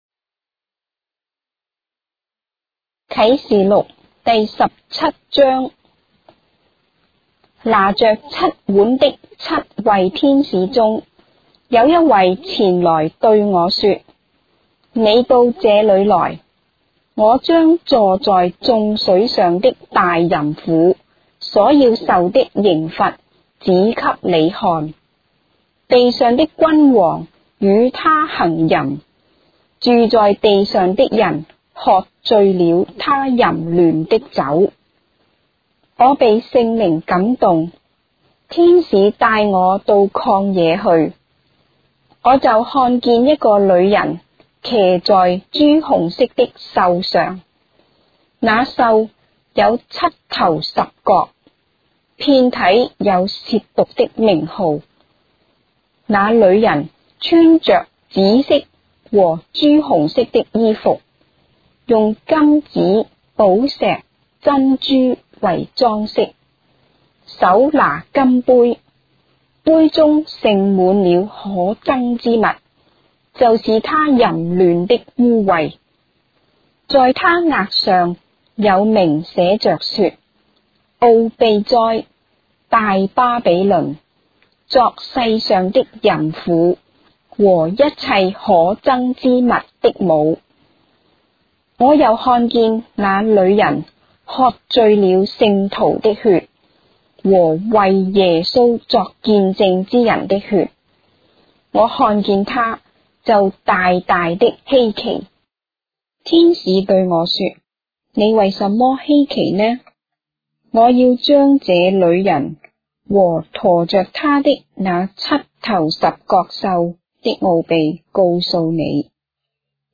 章的聖經在中國的語言，音頻旁白- Revelation, chapter 17 of the Holy Bible in Traditional Chinese